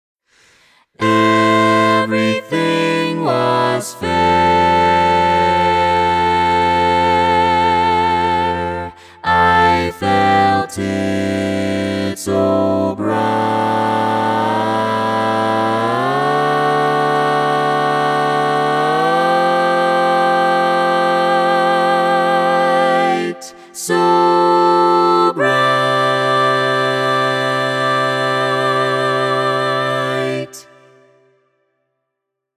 Key written in: C Major
Type: SATB
All Parts mix: